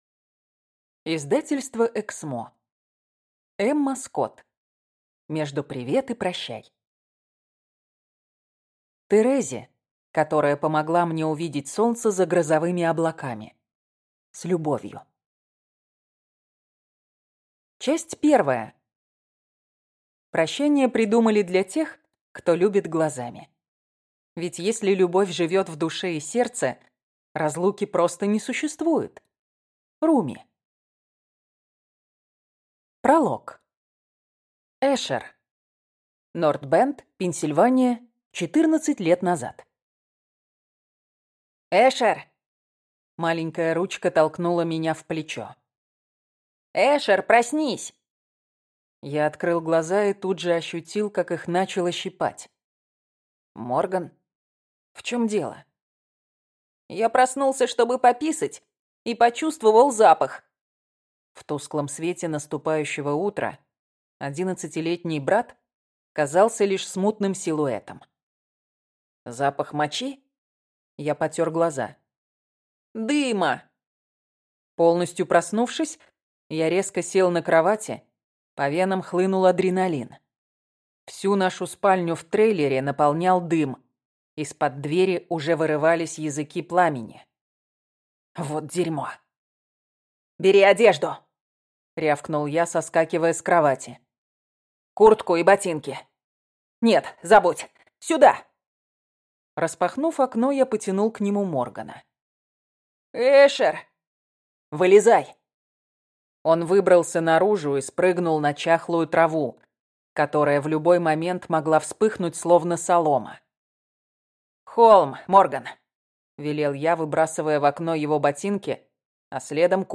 Аудиокнига Между «привет» и «прощай» | Библиотека аудиокниг